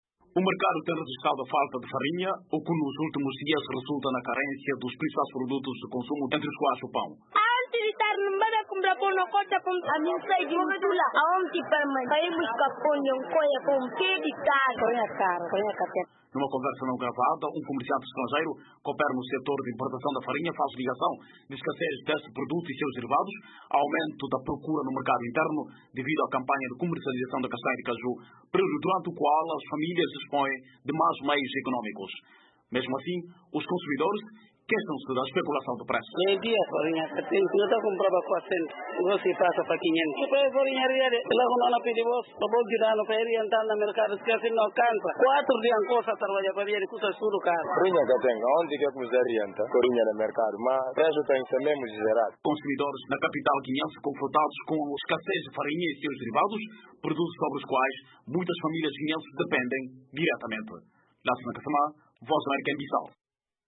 Acompanhe os depoimentos de alguns huineenses ouvidos pela VOA.